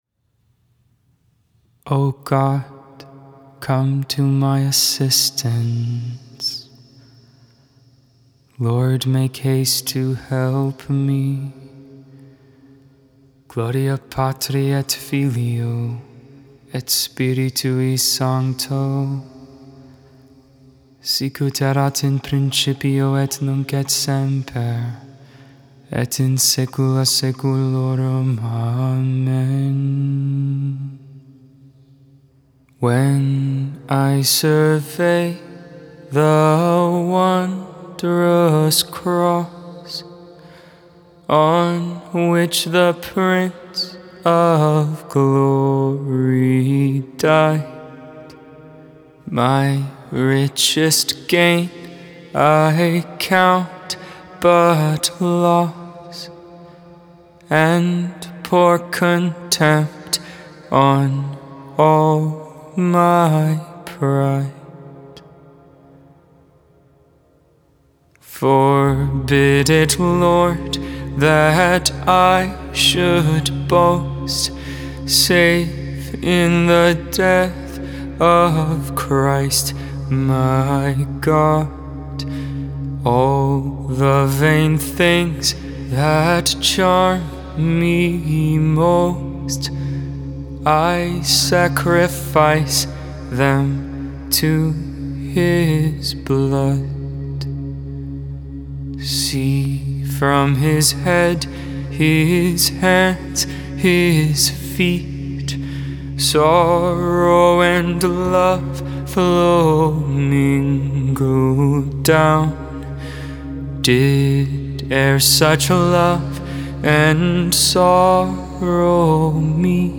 The Liturgy of the Hours: Sing the Hours 3.4.22 Vespers, Friday Evening Prayer Mar 04 2022 | 00:15:54 1x 00:00 / 00:15:54 Subscribe Share Spotify RSS Feed Share Link Embed